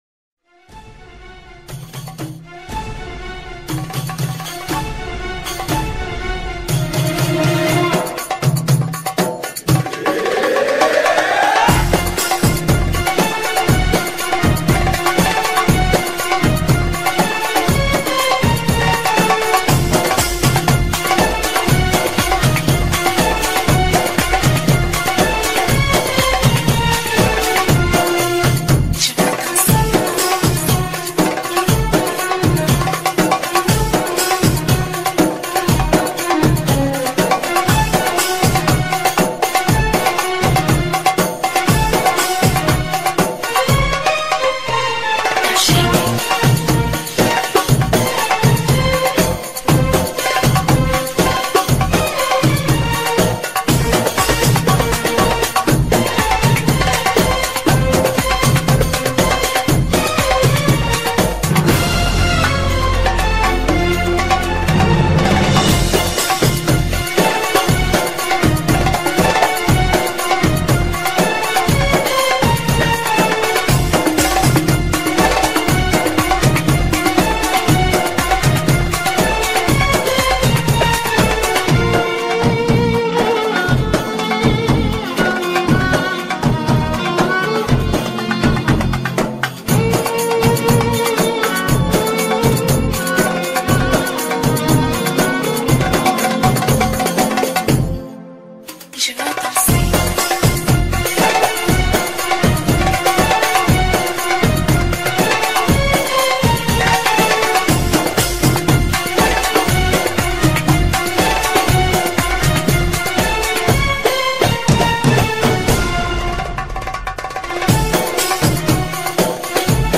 Arabic Belly Dance Music